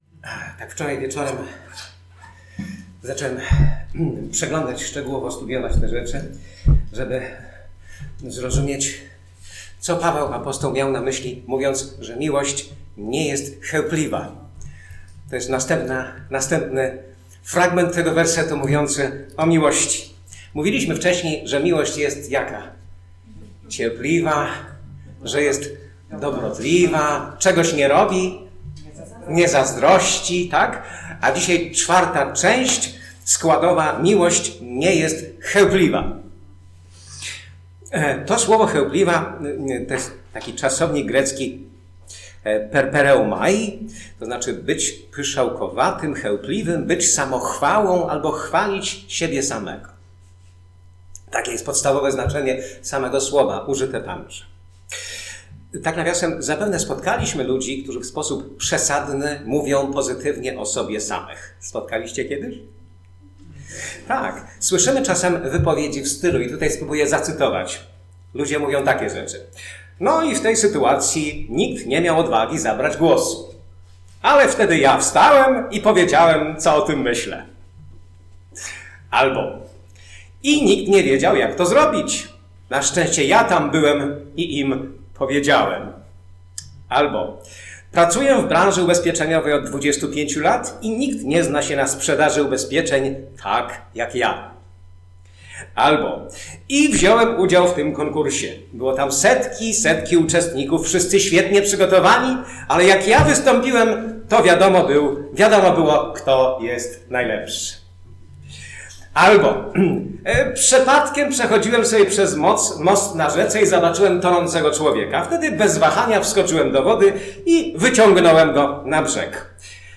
Kazanie
Posłuchaj kazań wygłoszonych w Zborze Słowo Życia w Olsztynie